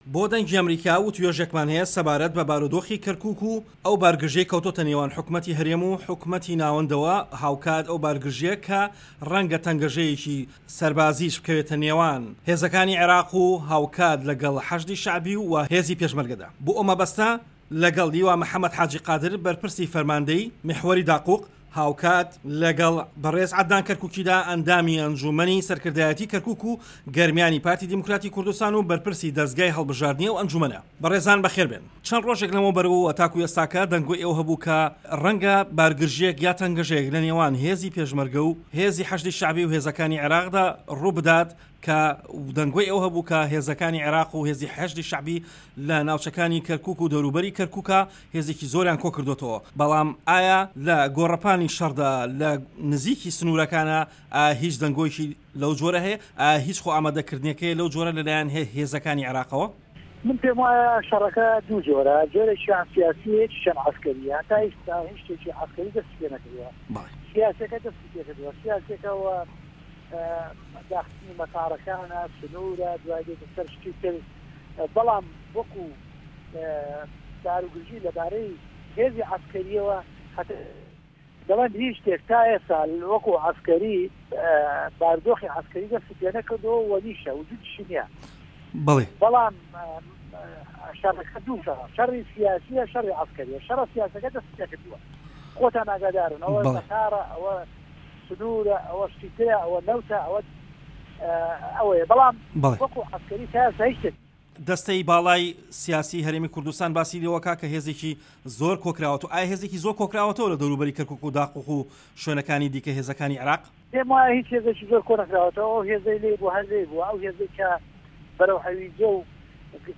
مێزگرد: ئایا هیچ هەڵوێستێکی لەشکری و بارگرژییەک لەنێوان هێزەکانی عێراق و پێشمەرگەدا بوونی هەیە